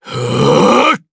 DarkKasel-Vox_Attack4_jp.wav